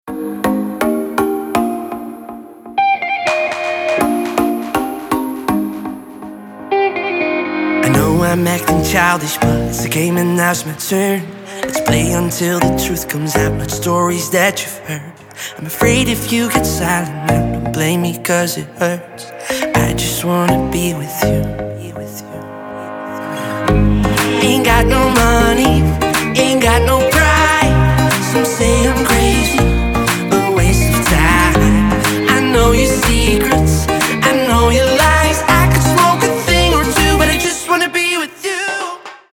• Качество: 192, Stereo
поп
мужской вокал
dance
vocal